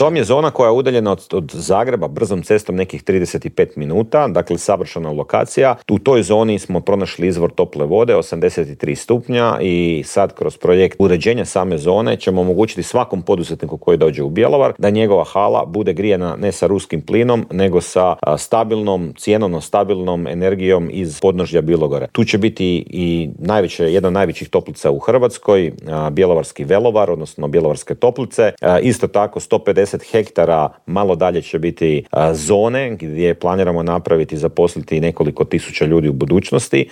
ZAGREB - "Bilo je i vrijeme da se gospodarske mjere relaksiraju, građani sada moraju preuzeti dio tereta na sebe. To neće ubrzati rast inflacije, a važno je da pomognemo onima koji su najugroženiji", u Intervjuu tjedna Media servisa poručio je saborski zastupnik iz redova HSLS-a Dario Hrebak.